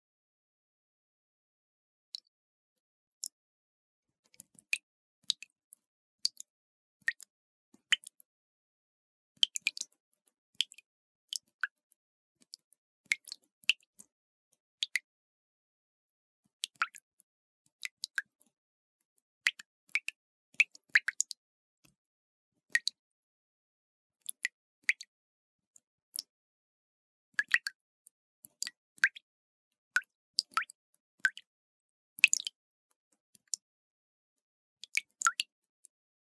На этой странице собраны разнообразные звуки сосулек — от нежного звона капель до резкого обрыва ледяных глыб.
Шепот тающей сосульки